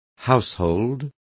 Complete with pronunciation of the translation of household.